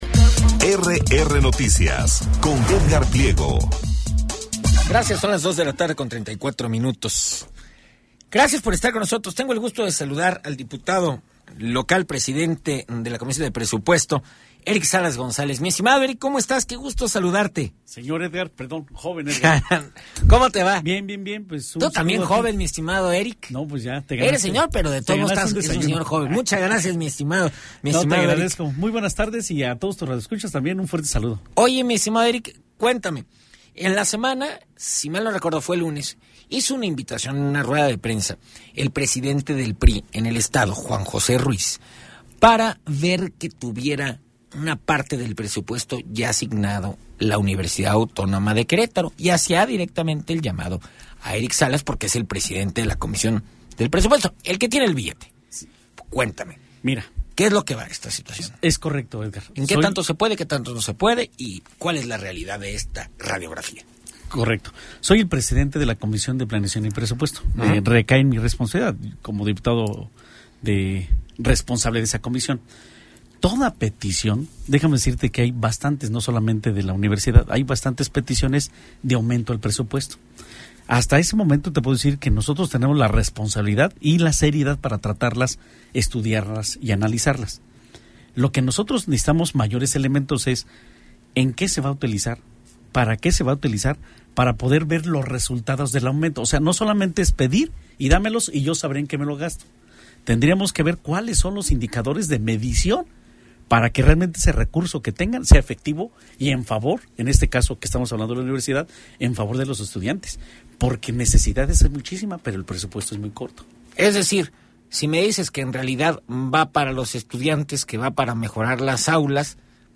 Entrevista con el diputado local Eric Salas en la segunda emisión de RR Noticias - RR Noticias